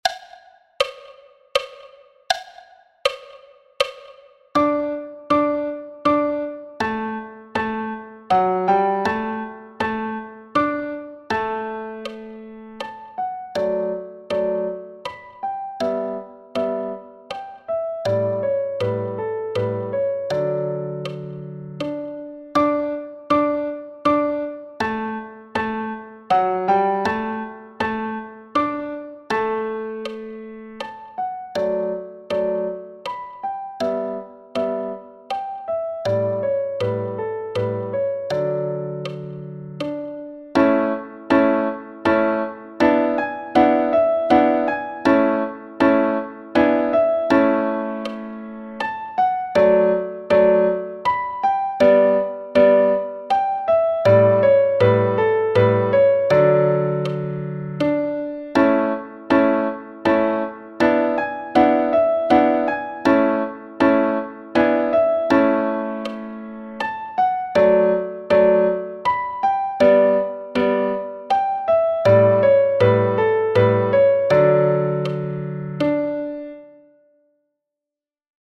Danse allemande – piano à 80 bpm
Danse-allemande-piano-a-80-bpm.mp3